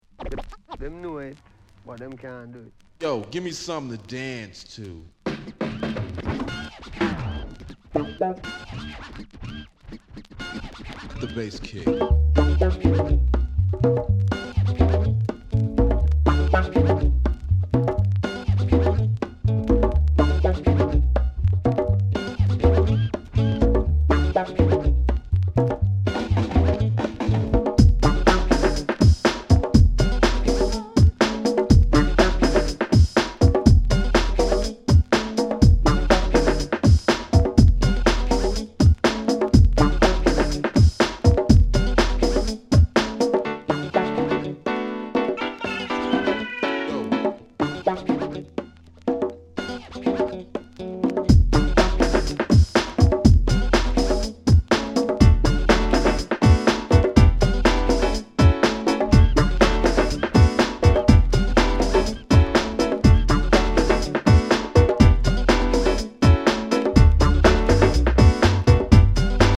一瞬キナ臭いように感じてしまいそうですが、これはこれで良いグッドグルーヴ。